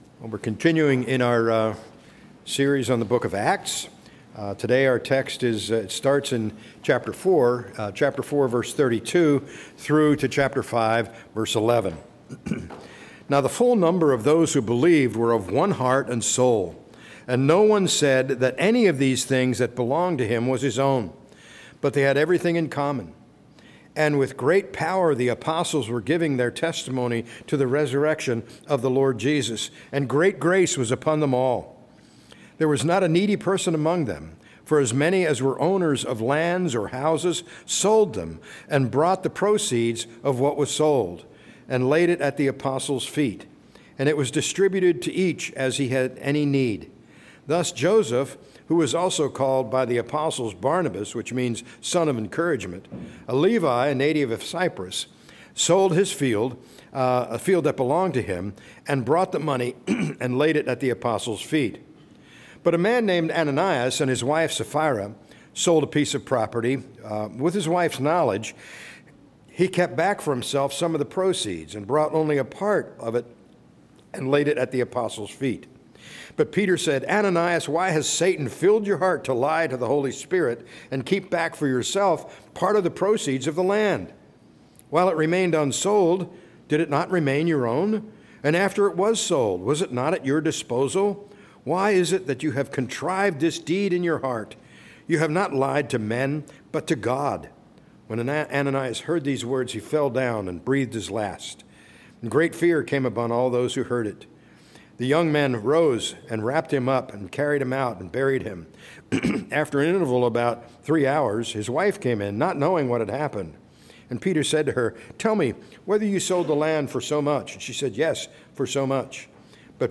A message from the series "Act 2025."